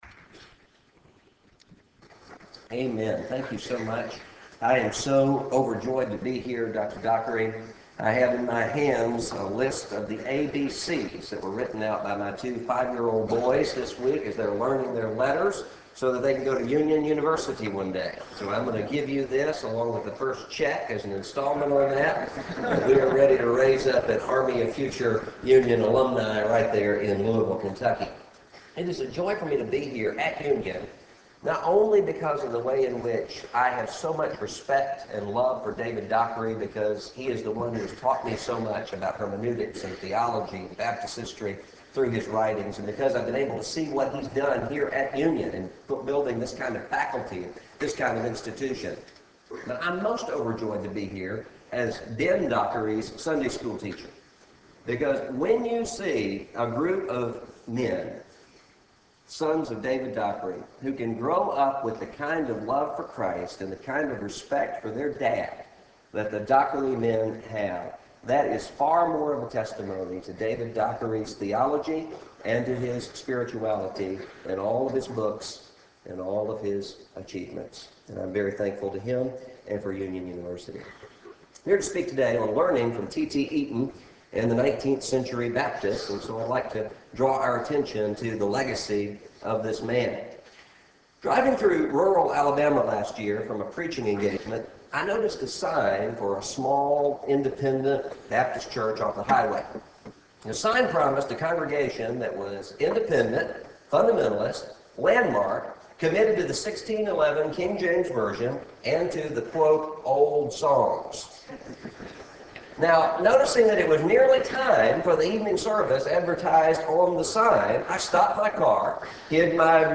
Baptist Identity & Founders Day Chapel: Russell Moore